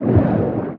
Sfx_creature_pinnacarid_swim_fast_03.ogg